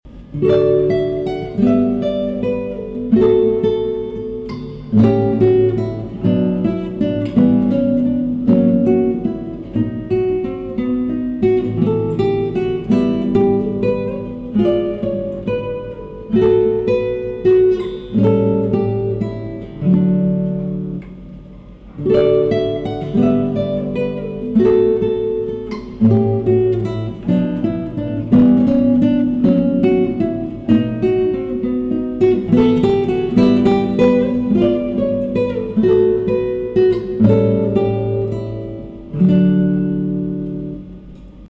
Un petit prelude de Tarrega en D enregistré avec audacity pardonnez moi pour la qualité d'enregistrement.
Je trouve ton son test bon, et l'interprétation aussi :biggrin:
je ne suis pas très equipé pour l'enregistrement juste audacity et juste le micro PC.
Magnifique ! bravissimo pour ton expression musicale, jon jeu, et le son de ta guitare !